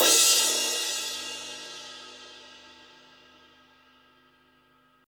Index of /90_sSampleCDs/Roland L-CD701/CYM_Crashes 1/CYM_Crsh Modules